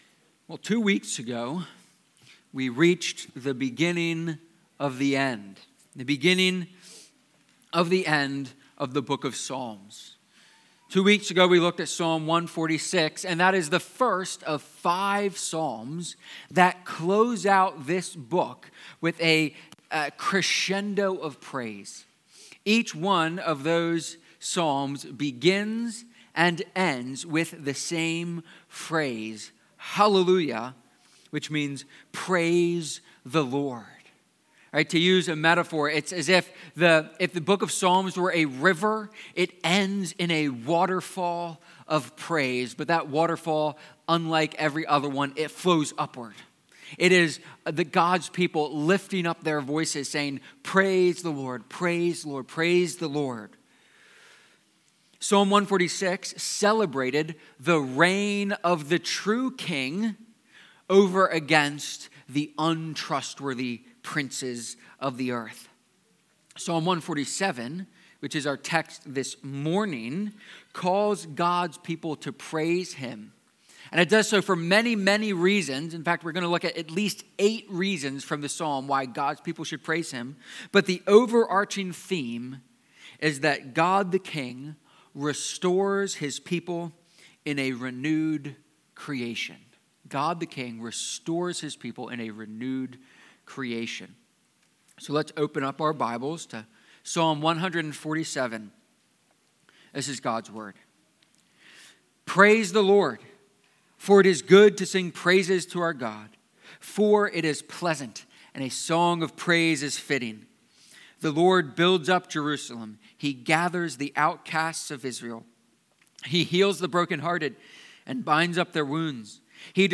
Psalm-147-sermon.mp3